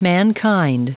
[m{n"kaInd]